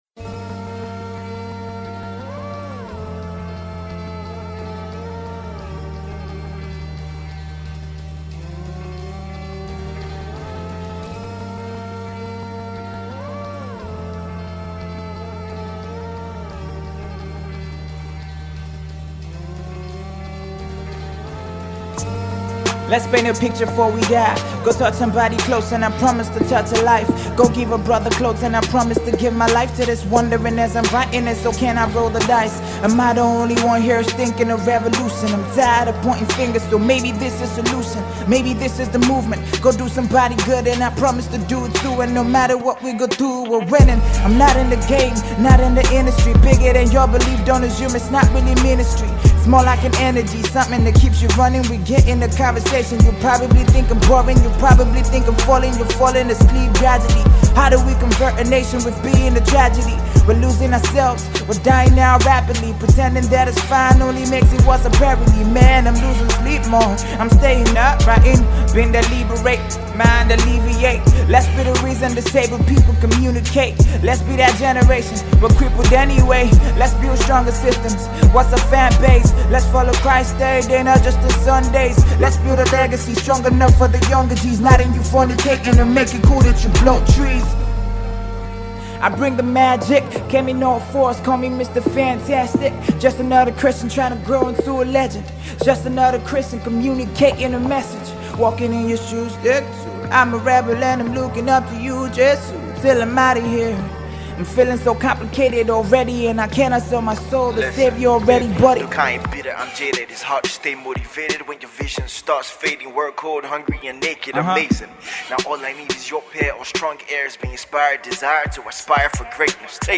On this soulful hip hop track